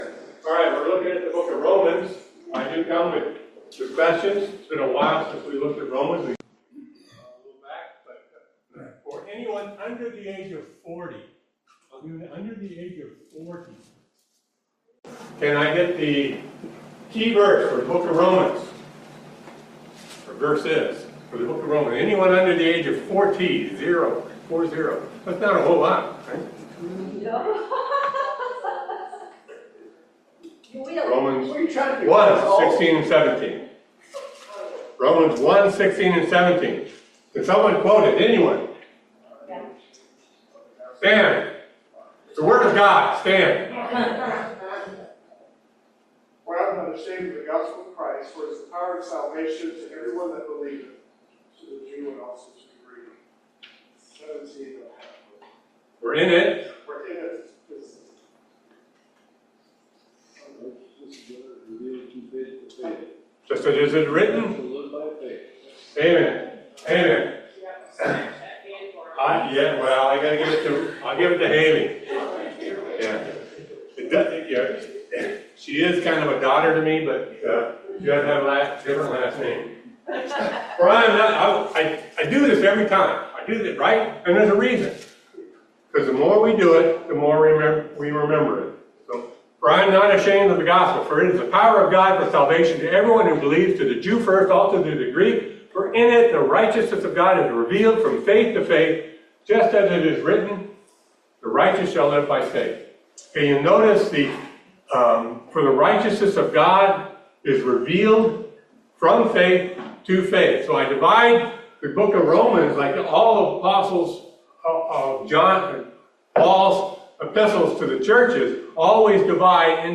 Romans 13:1-14 Service Type: Family Bible Hour Submit to authority and owe neighbors love.